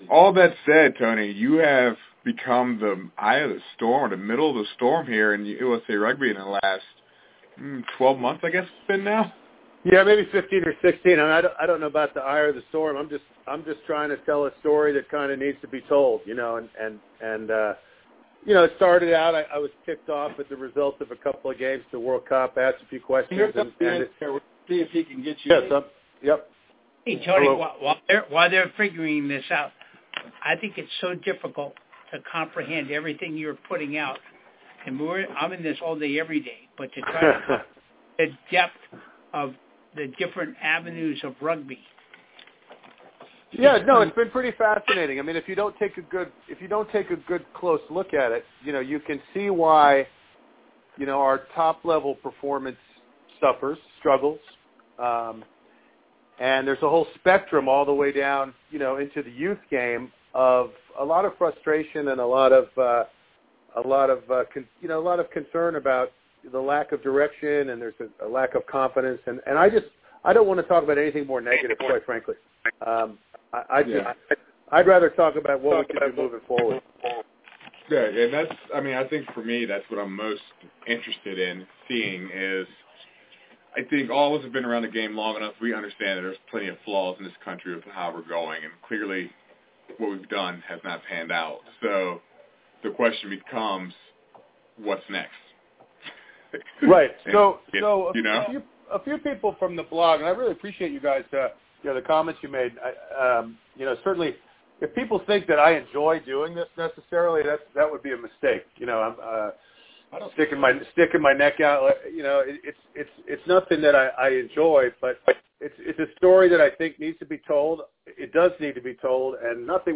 I was able to speak with “The Rugby Committee” tonight on their radio show and discuss USA Rugby.